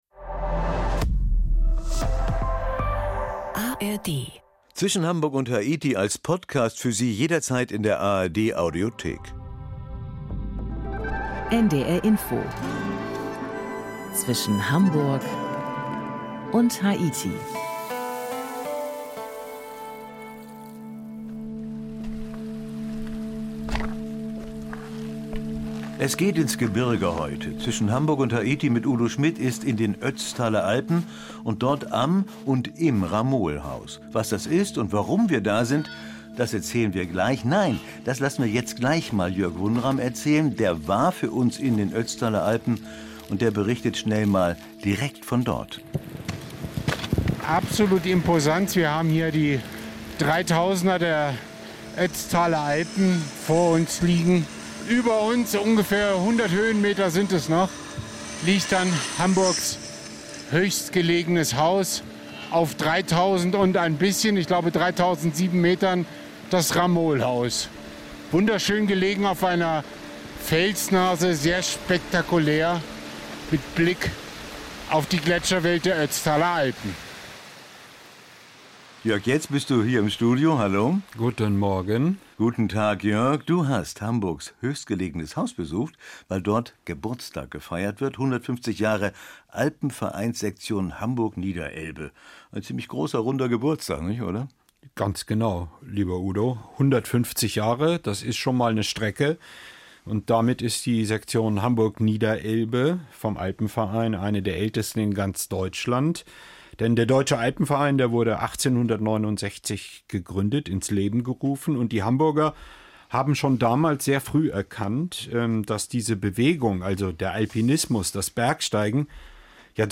Reportagen